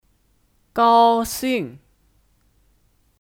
高兴 (Gāoxìng 高兴)